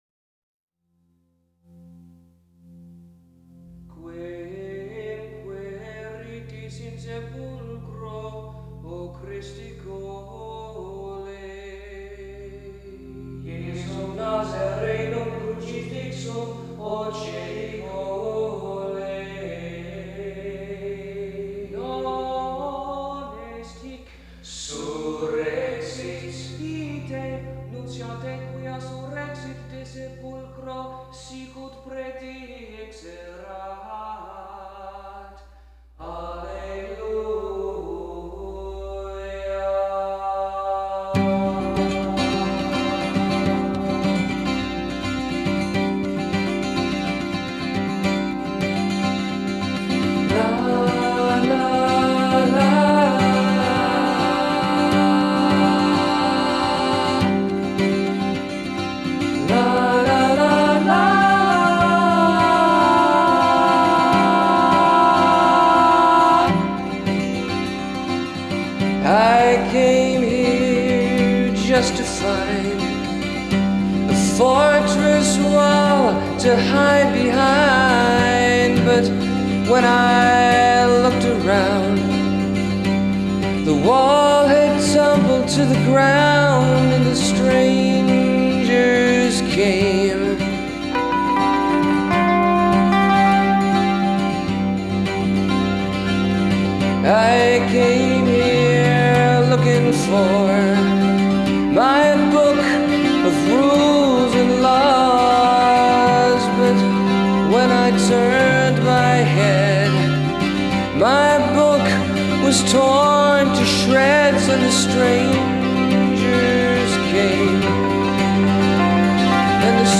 guitars, vocals
cello